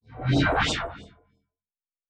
Sci-Fi Sounds / Movement / Synth Whoosh 5_2.wav
Synth Whoosh 5_2.wav